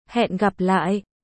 1. Âm thanh lời chào tiếng việt